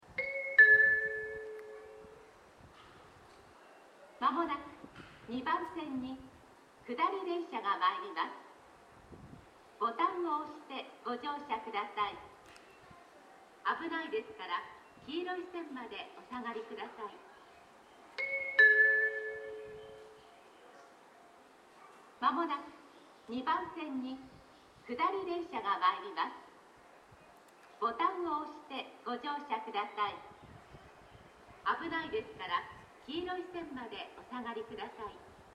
箱根ヶ崎駅２番線接近放送　　普通　川越行き接近放送です。簡易型です。